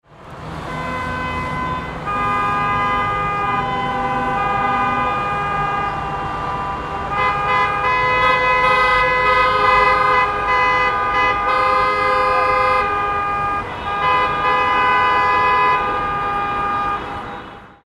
Nervous Car Horns: Authentic City Traffic Sounds
Nervous Car Horns – City Street Traffic Sound Effect
Authentic and loud car horn sounds in busy city traffic.
Street sounds.
Nervous-car-horns–city-street-traffic-sound-effect.mp3